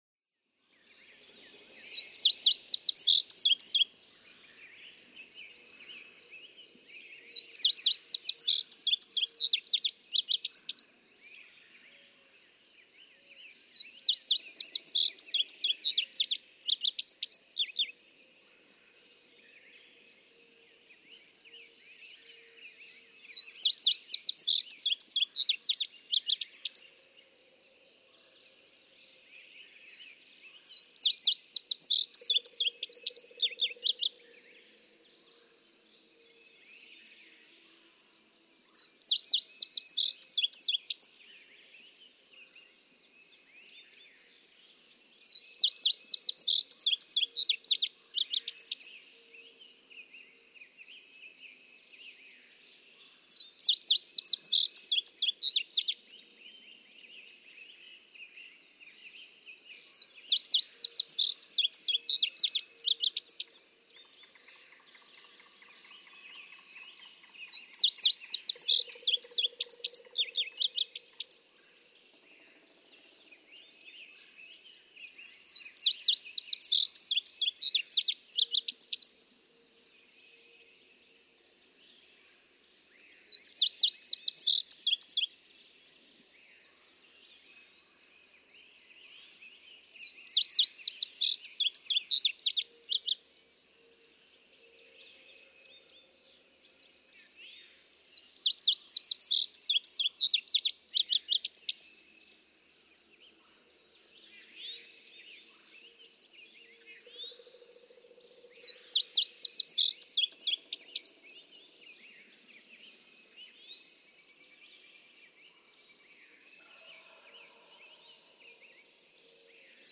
Francis Marion National Forest, South Carolina.
♫207, ♫208—longer recordings from those two neighbors
207_Indigo_Bunting.mp3